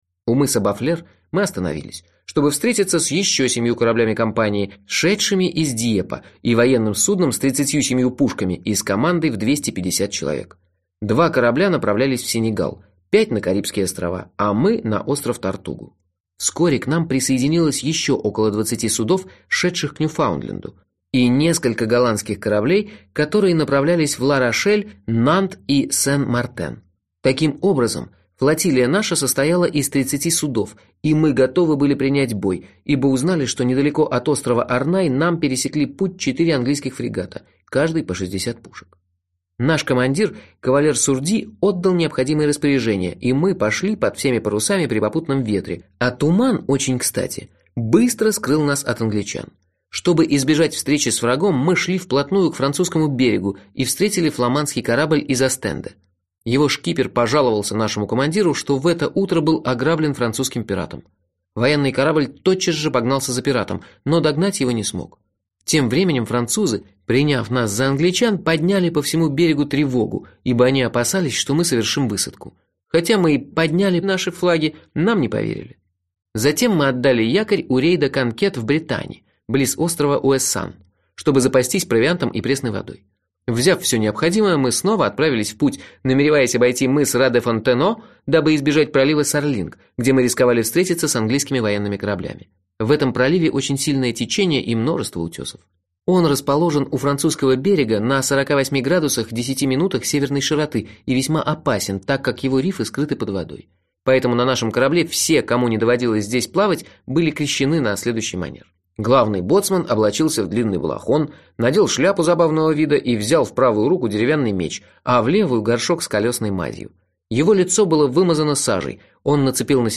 Аудиокнига Карибские пираты | Библиотека аудиокниг